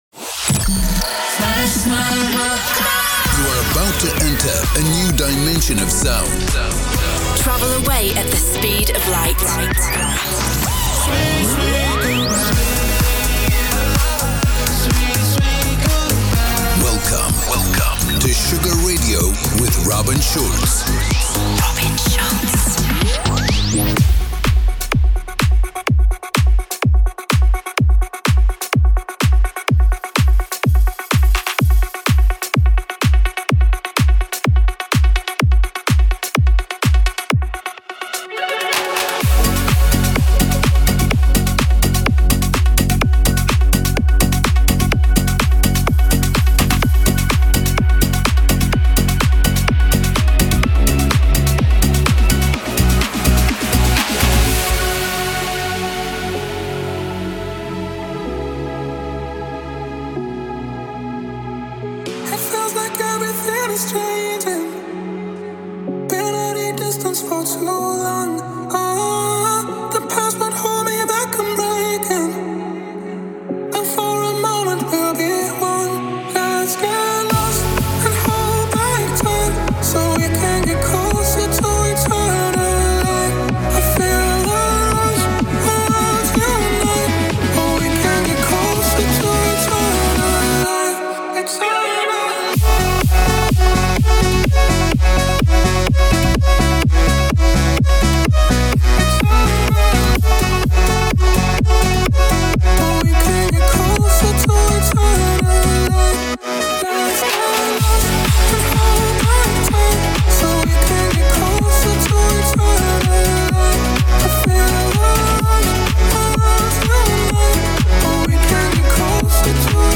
Genre: Electro Pop